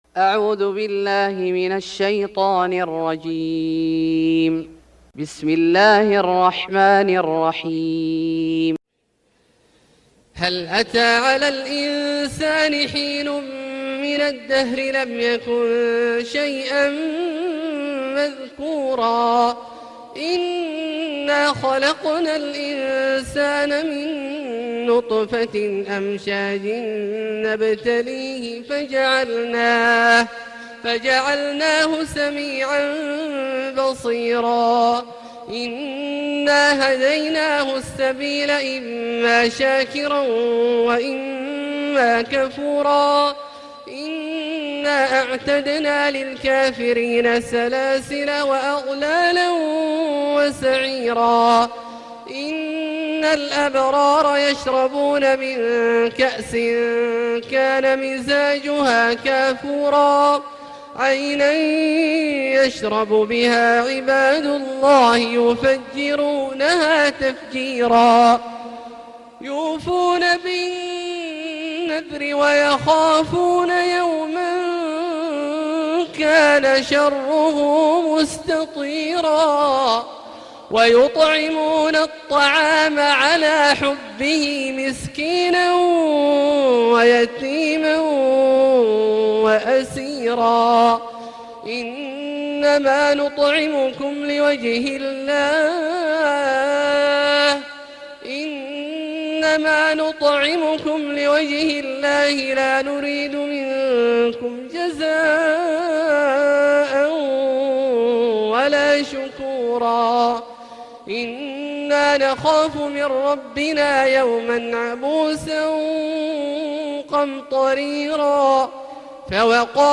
سورة الإنسان Surat Al-Insane > مصحف الشيخ عبدالله الجهني من الحرم المكي > المصحف - تلاوات الحرمين